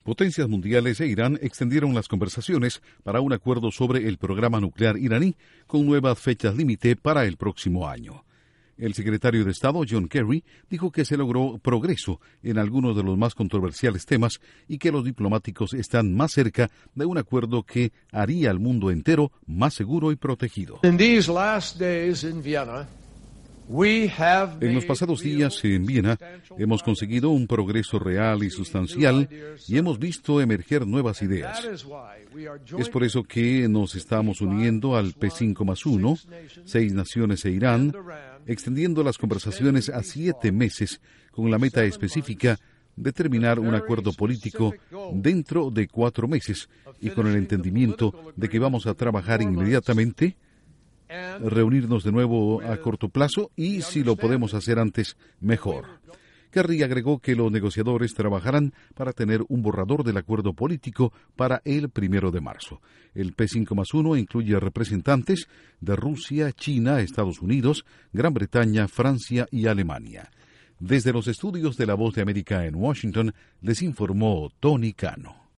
Extienden plazo para un acuerdo sobre el programa nuclear de Irán. Informa desde los estudios de la Voz de América